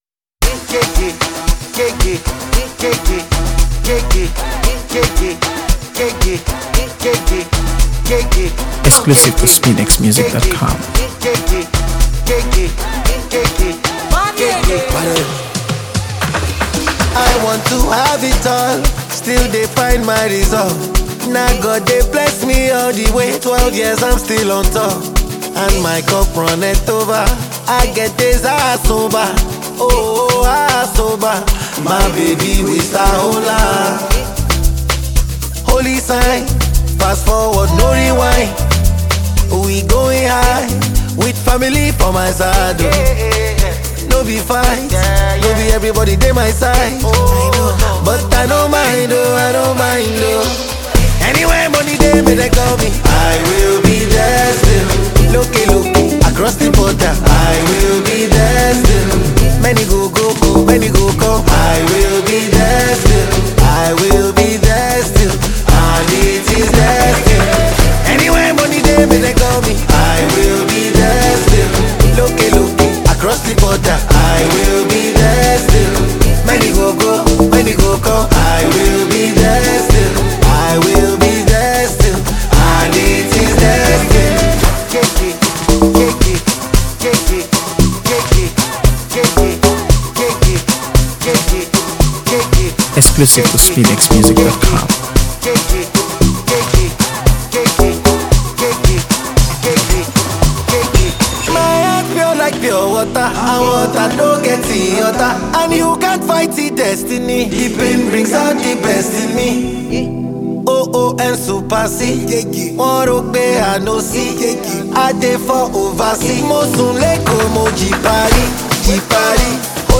AfroBeats | AfroBeats songs
blending smooth melodies with heartfelt lyrics